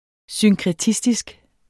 Udtale [ synkʁεˈtisdisg ]